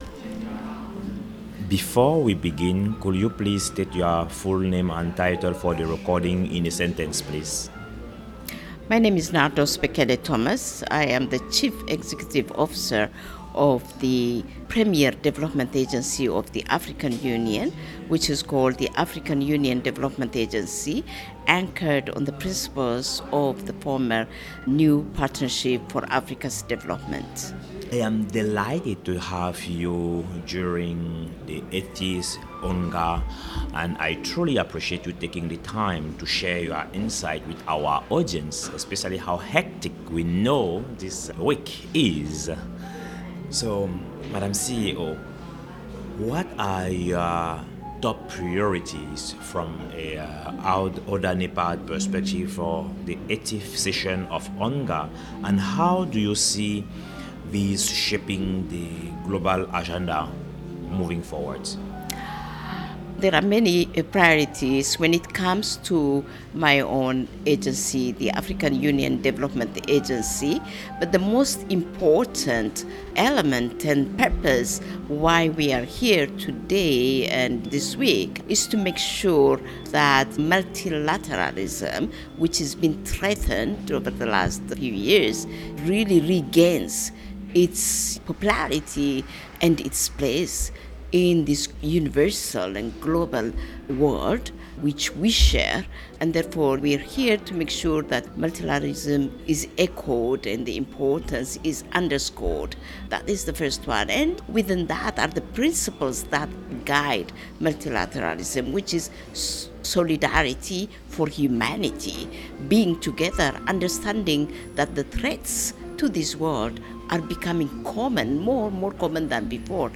Listen to the first episode of the three-part series, which includes interviews with three other speakers on site at the event.